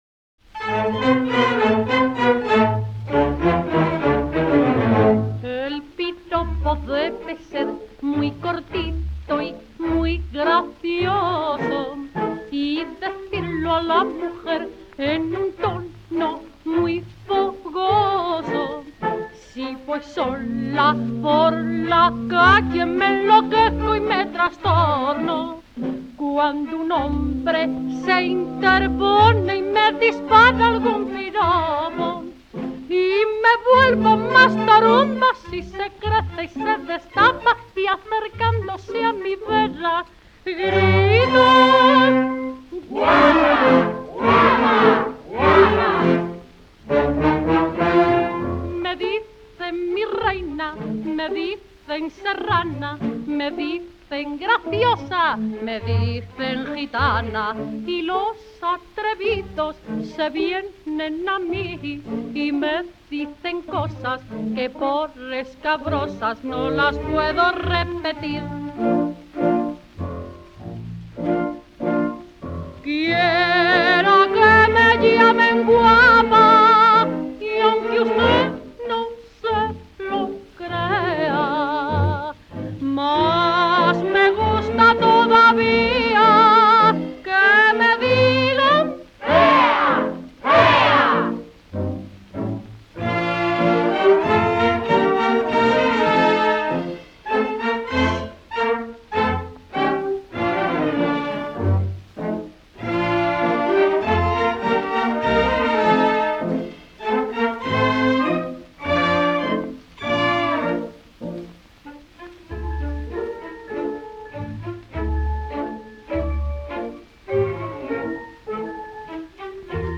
(piropos, schottis) ("El piropo debe ser...").
78 rpm